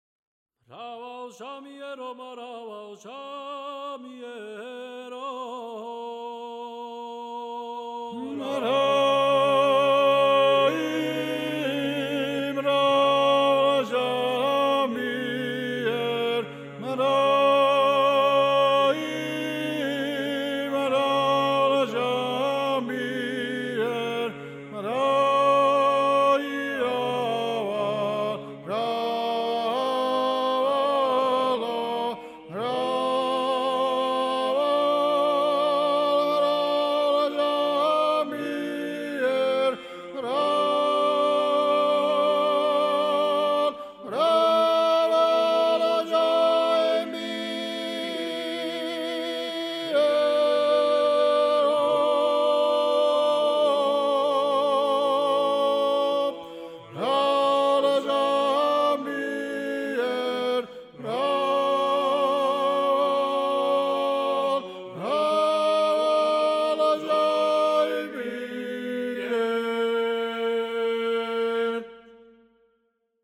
სასწავლო ჩანაწერი I ხმ